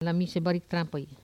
Localisation Saint-Jean-de-Monts
Langue Maraîchin
Catégorie Locution